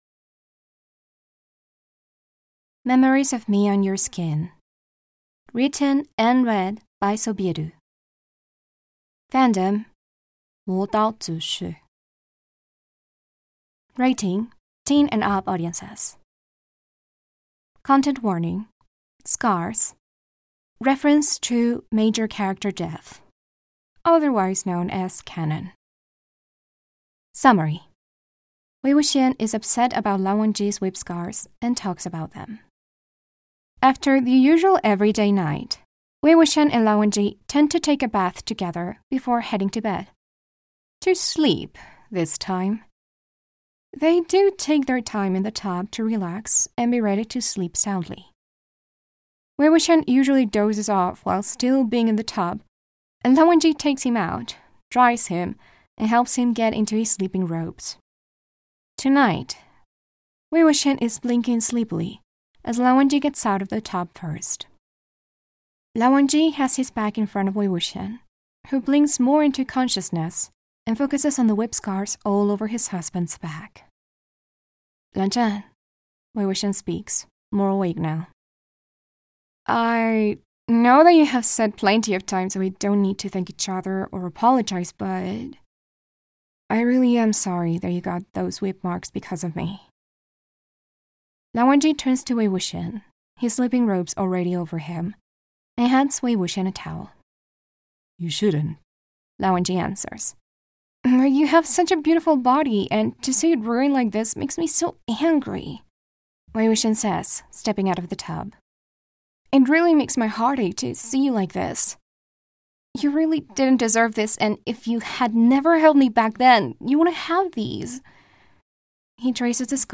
with music & sfx: download mp3: here (r-click or press, and 'save link') [31 MB, 00:21:05]